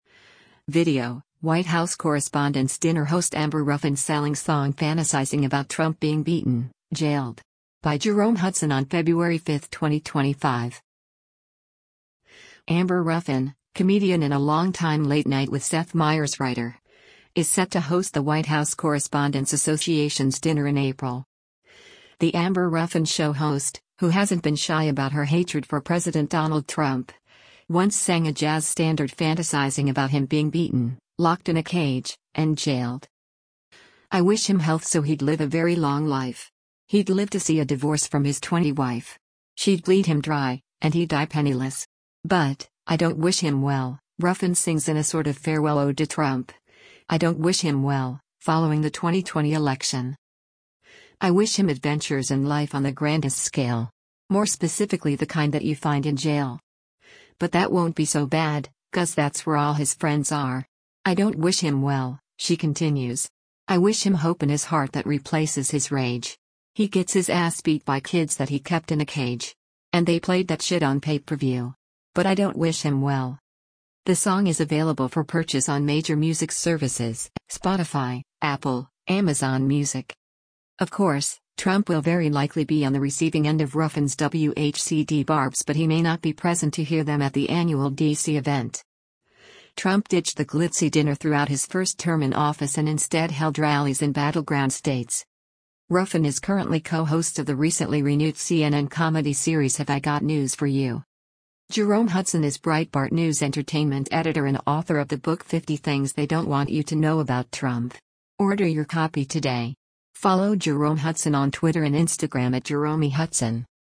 once sang a jazz standard fantasizing about him being beaten